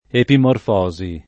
epimorfosi
vai all'elenco alfabetico delle voci ingrandisci il carattere 100% rimpicciolisci il carattere stampa invia tramite posta elettronica codividi su Facebook epimorfosi [ epimorf 0@ i ; alla greca epim 0 rfo @ i ] s. f. (biol.)